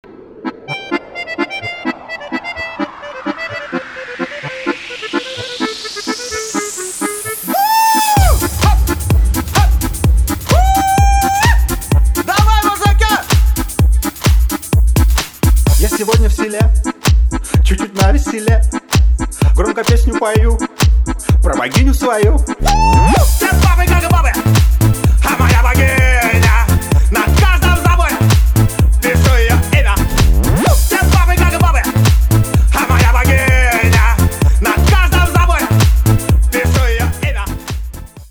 • Качество: 320, Stereo
забавные
dance